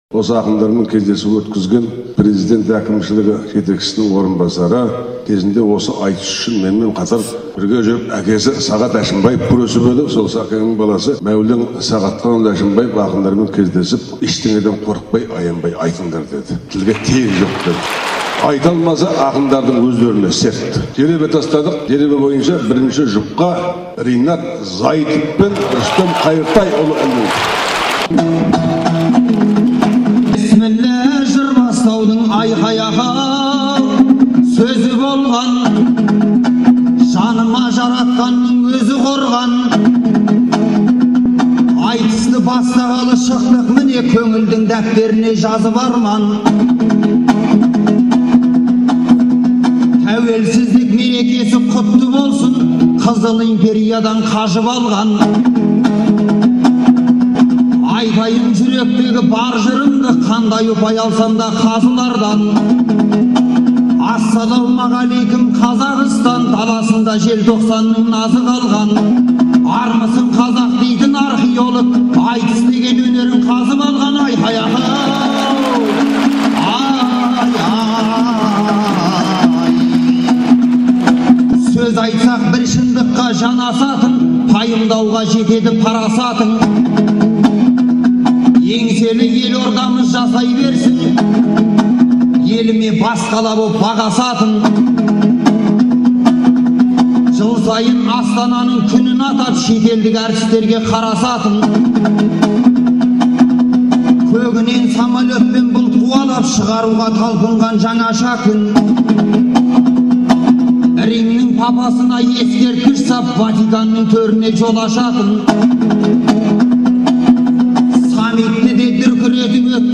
айтысы
Астана, 13 желтоқсан 2010 жыл.